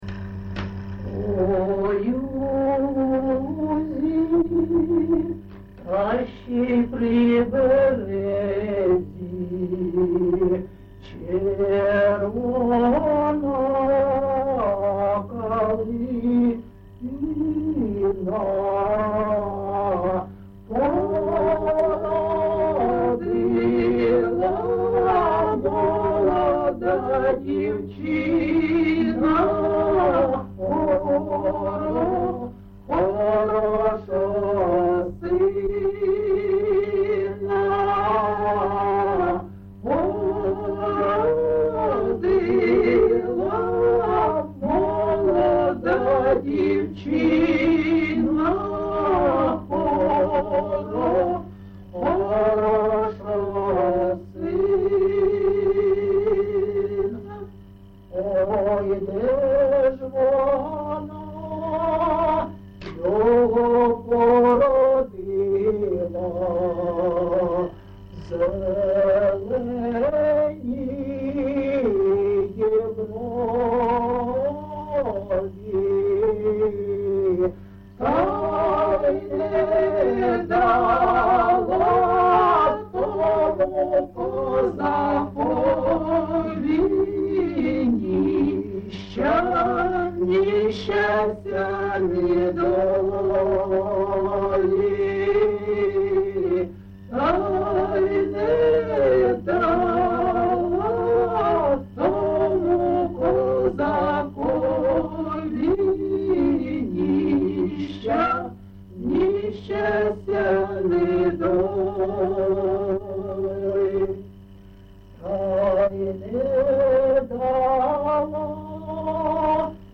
ЖанрПісні з особистого та родинного життя
Місце записум. Костянтинівка, Краматорський район, Донецька обл., Україна, Слобожанщина